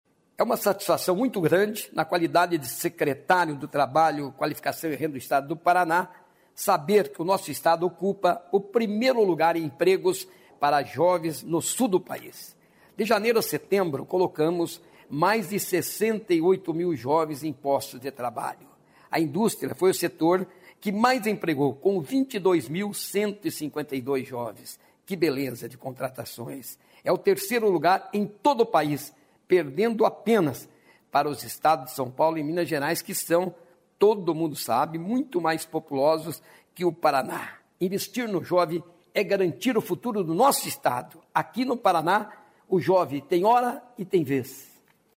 Sonora do secretário do Trabalho, Qualificação e Renda, Mauro Moraes, sobre a empregabilidade de jovens no Estado